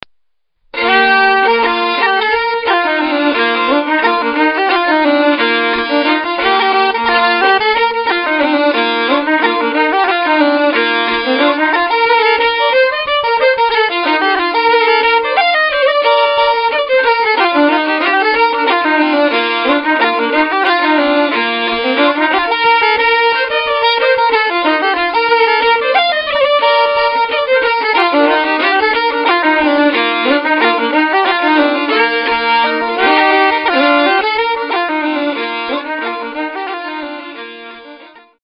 The music on this CD is played in the pitches of B and Bb.
Thus the sound is mellow and smooth.